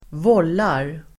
Uttal: [²v'ål:ar]